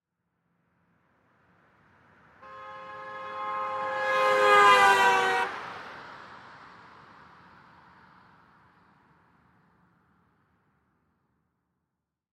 Звуки дорожного движения
Звук автомобиля с сигналом - Дополнительные детали